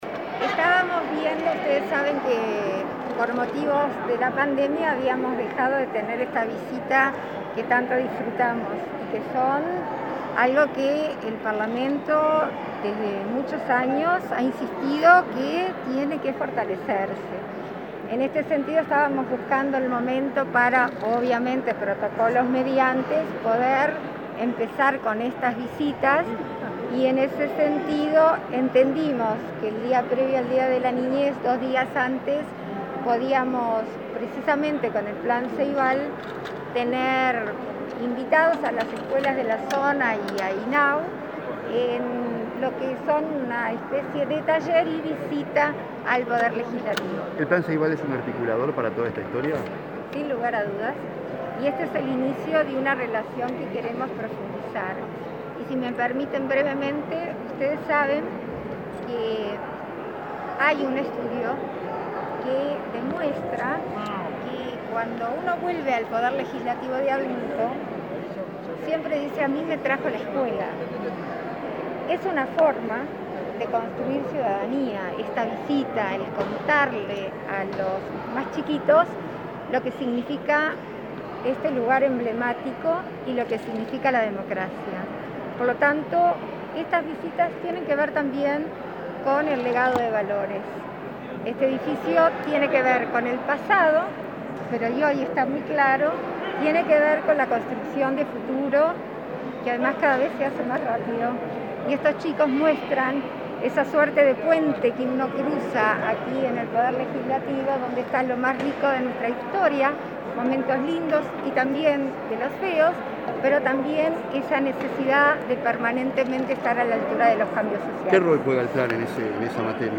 Declaraciones de la vicepresidenta de la República, Beatriz Argimón